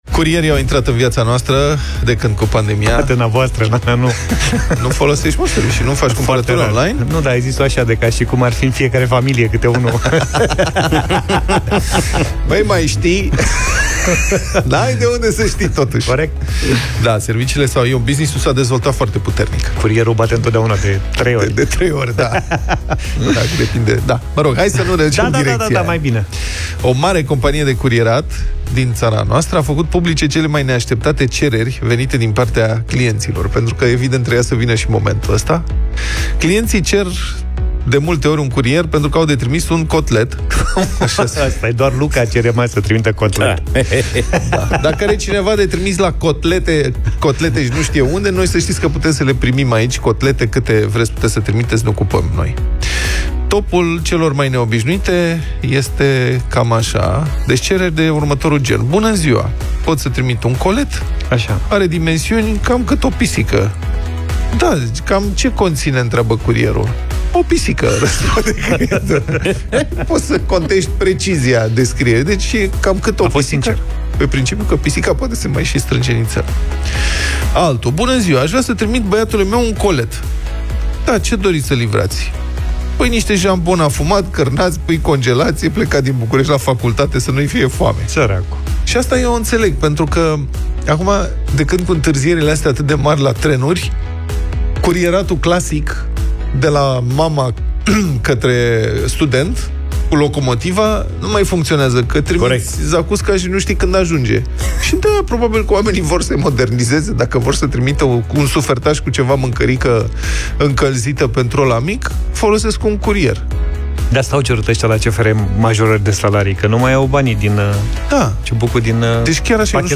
au vorbit despre acest subiect în Deșteptarea.